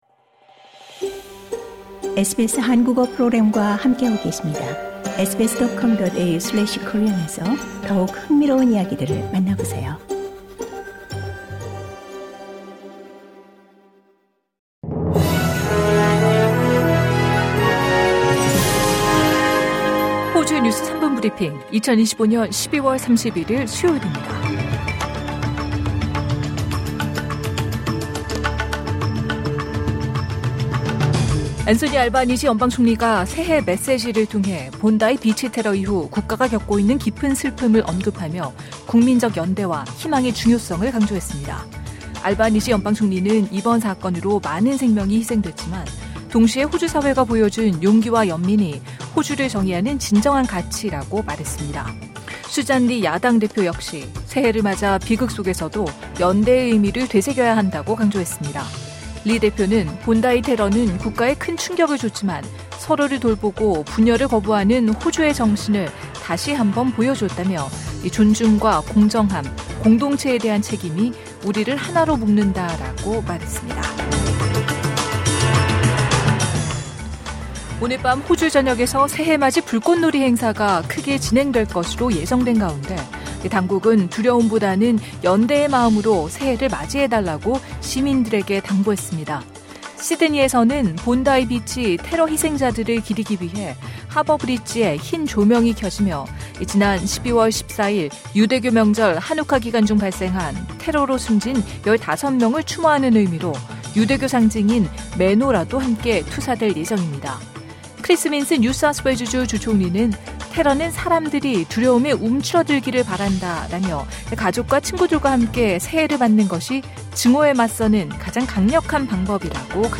한중 정상회담 열려 LISTEN TO 호주 뉴스 3분 브리핑: 2025년 12월 31일 수요일 SBS Korean 03:57 Korean 이 시각 간추린 주요 뉴스 앤소니 알바니지 연방 총리가 새해 메시지를 통해, 본다이 비치 테러 이후 국가가 겪고 있는 깊은 슬픔을 언급하며 국민적 연대와 희망의 중요성을 강조했습니다.